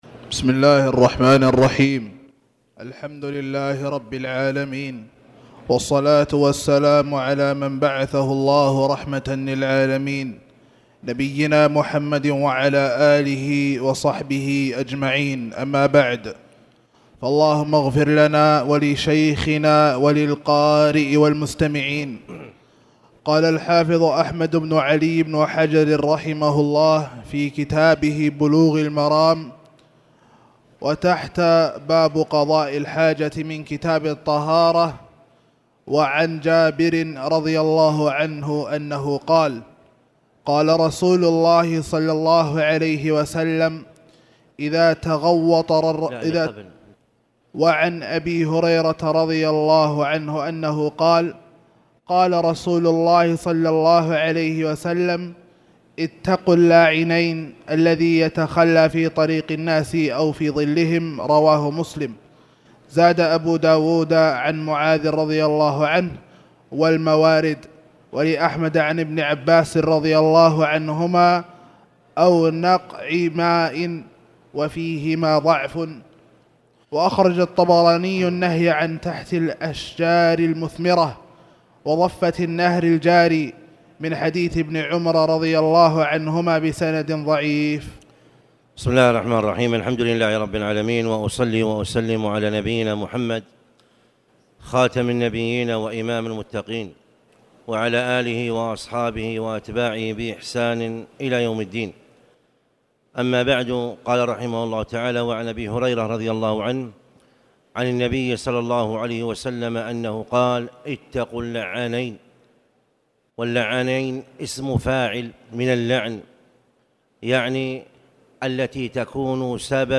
تاريخ النشر ٢١ ربيع الثاني ١٤٣٨ هـ المكان: المسجد الحرام الشيخ